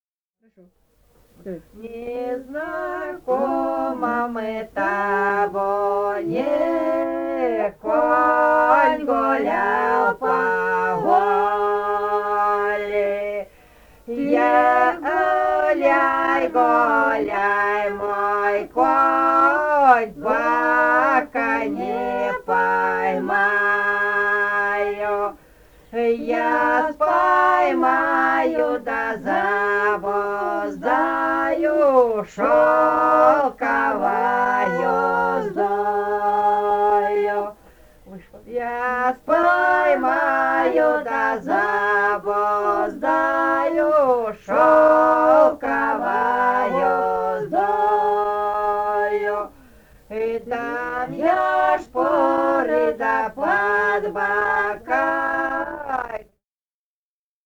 Этномузыкологические исследования и полевые материалы
Бурятия, с. Петропавловка Джидинского района, 1966 г. И0903-06